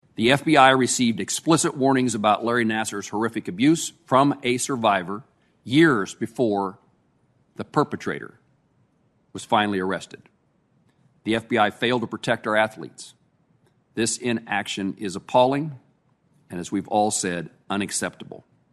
U.S. Senator Jerry Moran offered the opening statements Wednesday morning as the Senate Judiciary dealt with the FBI’s handling of the sexual assaults of multiple gymnasts by former Olympic and Michigan State doctor Larry Nassar.